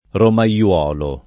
vai all'elenco alfabetico delle voci ingrandisci il carattere 100% rimpicciolisci il carattere stampa invia tramite posta elettronica codividi su Facebook romaiolo [ roma L0 lo ] (lett. romaiuolo [ roma LU0 lo ]) → ramaiolo